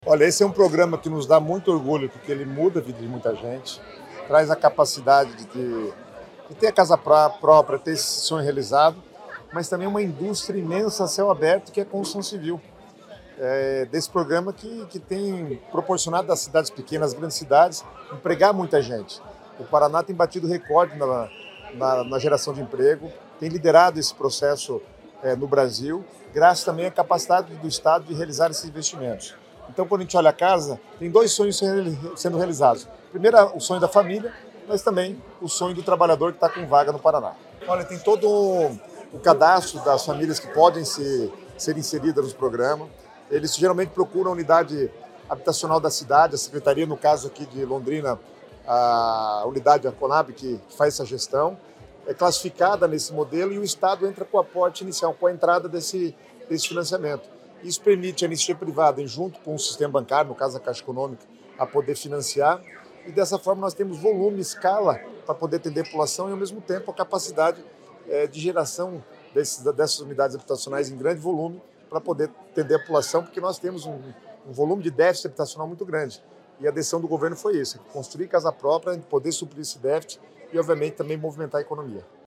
Sonora do secretário das Cidades, Guto Silva, sobre a entrega de residencial com 128 apartamentos para atender famílias de Londrina